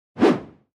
wind UI.mp3